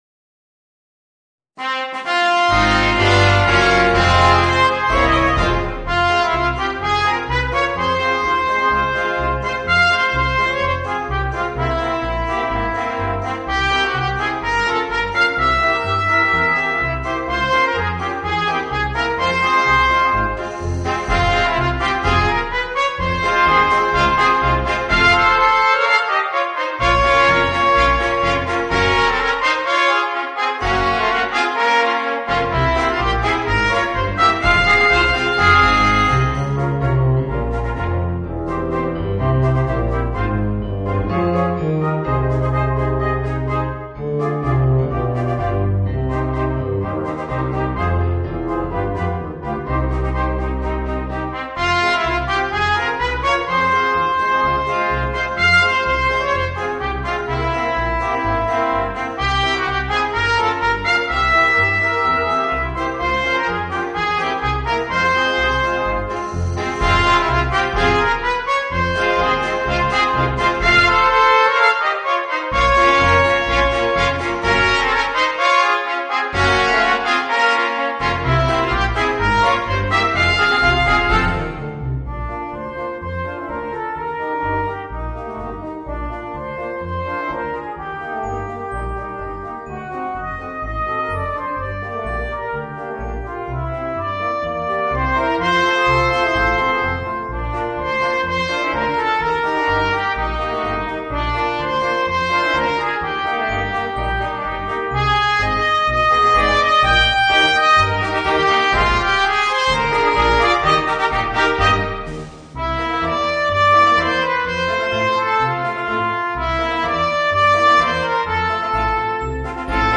Voicing: 2 Trumpets, Horn, Trombone and Drums